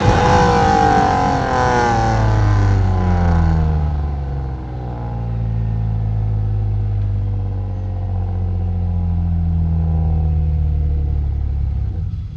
rr3-assets/files/.depot/audio/Vehicles/i6_02/i6_02_decel.wav
i6_02_decel.wav